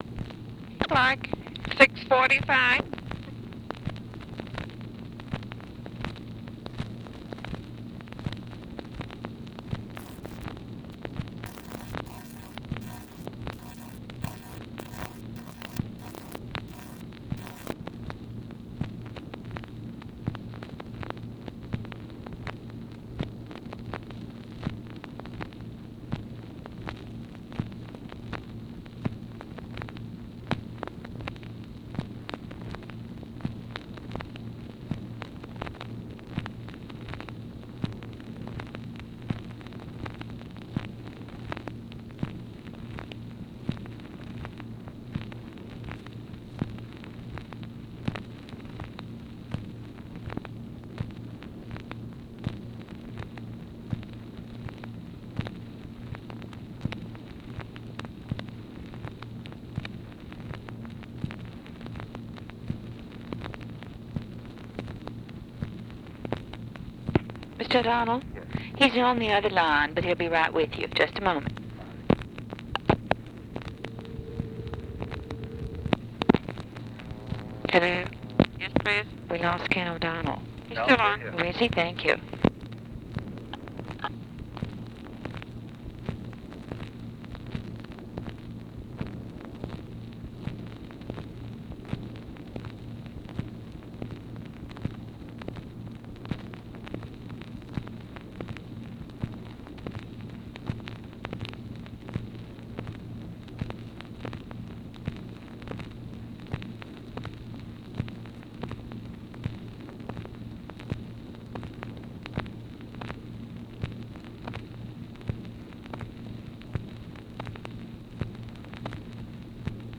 Conversation with KEN O'DONNELL, August 21, 1964
Secret White House Tapes